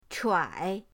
chuai3.mp3